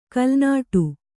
♪ kalnāṭu